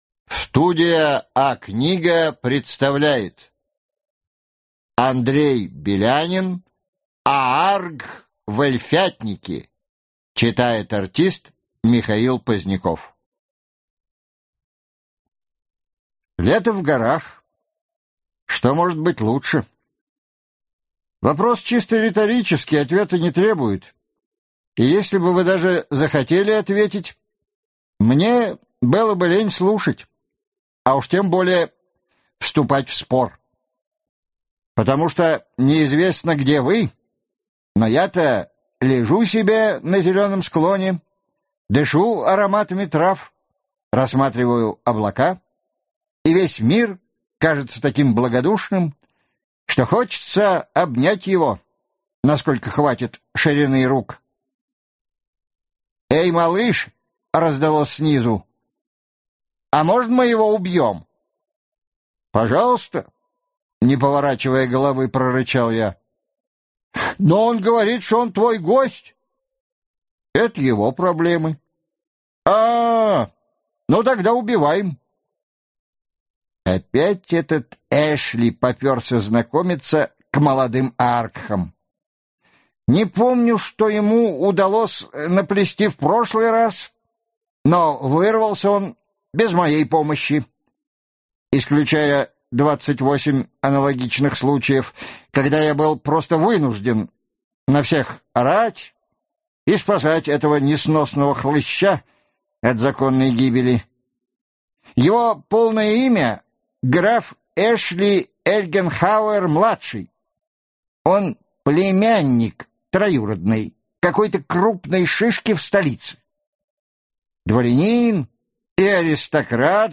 Аудиокнига Ааргх в эльфятнике | Библиотека аудиокниг
Прослушать и бесплатно скачать фрагмент аудиокниги